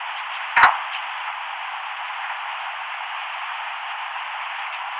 Earthquakes
Here are a couple of examples of how earthquakes signals can sound.
The sounds you hear represent the sudden release of acoustic/seismic energy. Some people say it sounds like a slamming door.